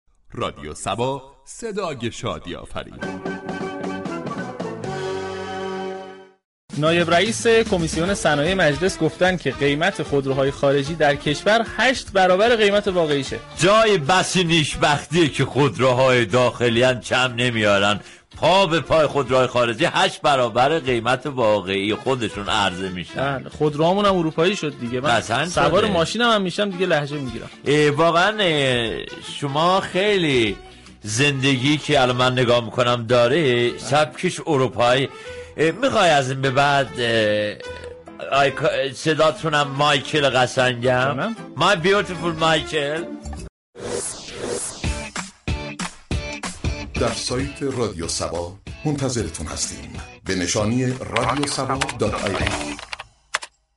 سیدجواد حسینی كیا، نایب رئیس كمیسیون صنایع مجلس با حضور در استودیو خبر 21 پنجشنبه شب شبكه یك سیما درباره تصویب طرح واردات خودرو در مجلس اظهار كرد: این مصوبه، اصلاح ماده 4 اعاده شده از شورای نگهبان بود كه با این اصلاح در ازای صادرات خودرو، قطعات خودرو و خدمات در این راستا بتوانیم به میزان محدودی كه شورای رقابت مشخص می‌‌كند واردات خودرو داشته باشیم.